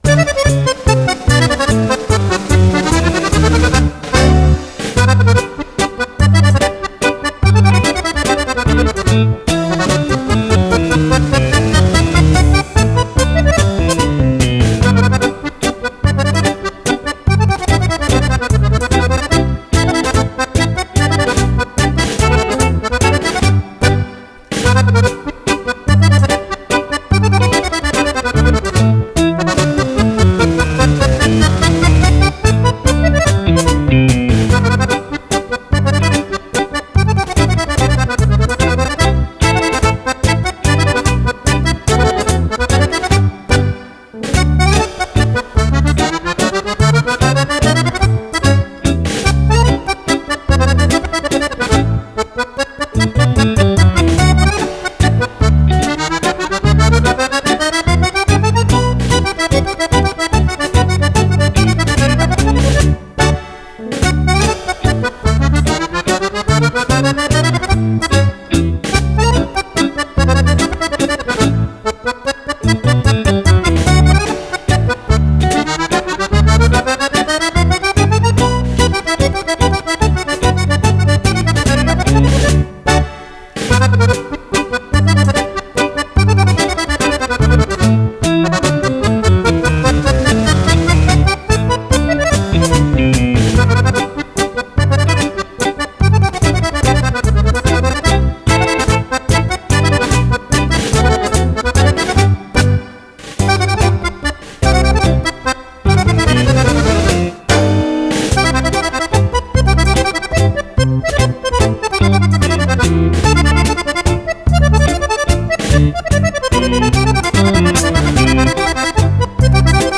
Mazurka
2 Ballabili per Fisarmonica
LISCIO ALLA VECCHIA MANIERA